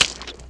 gibhit3.wav